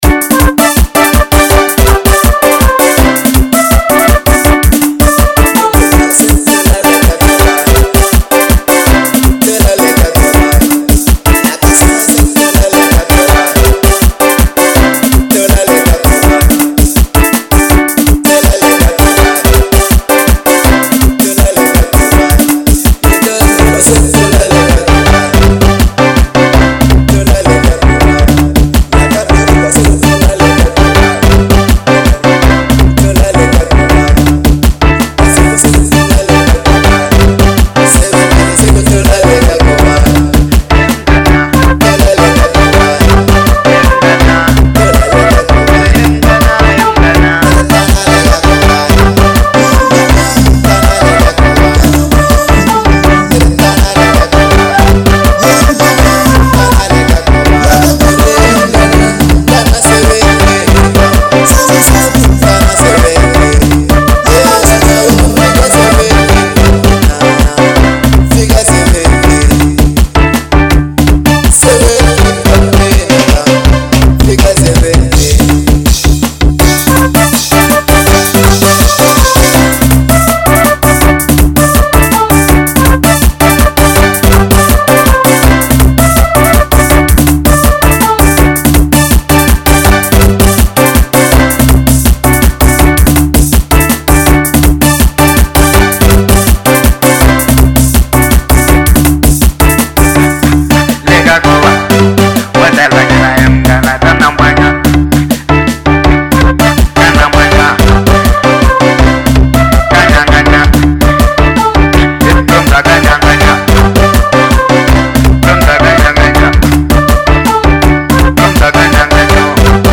03:23 Genre : Xitsonga Size